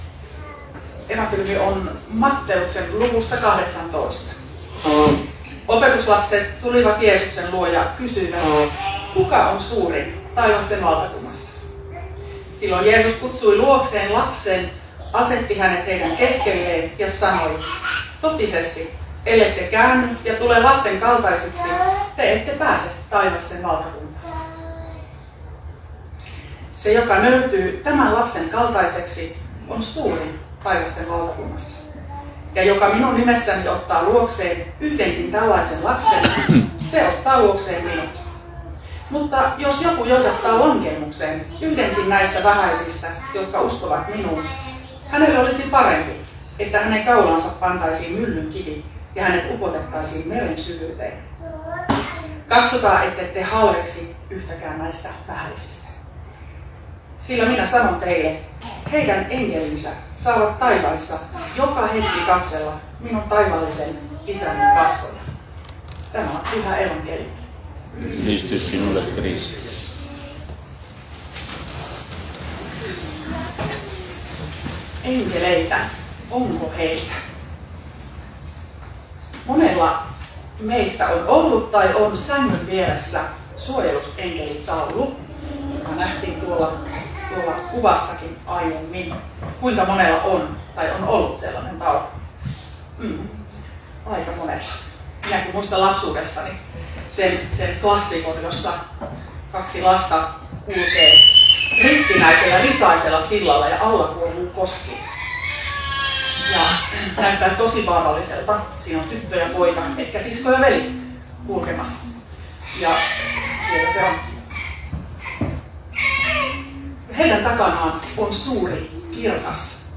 saarna Kannuksessa mikkelinpäivänä